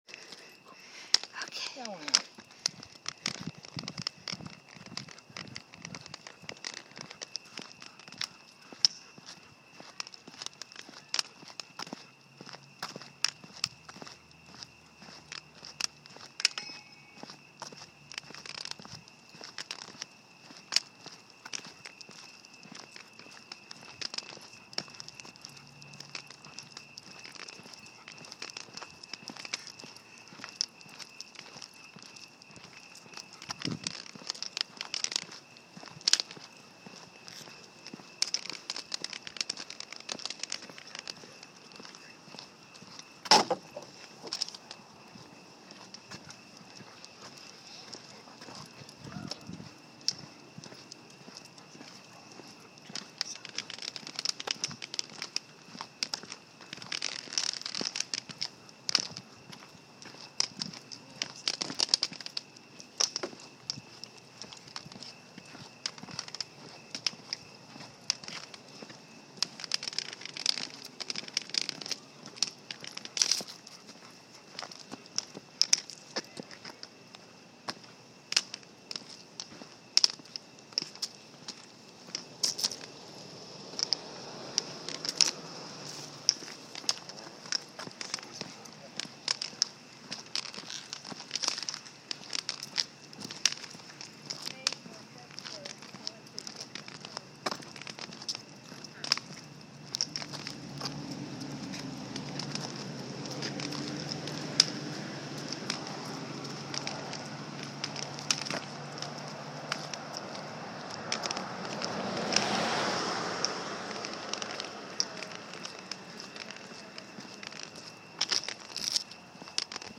The crickets were chirping, and it was such a peaceful night.
One might think that one recorded walk is the same as another.
It was quiet except for the crickets in many places. However when we returned two our street, you could tell that we were heading back to more city like and noisier territory.
When I even tried to get the backyard perspective , You could hear the neighbors dryer or the strip mall fans. Yes, the crickets can be heard above the noise, but who wants to hear the city noise.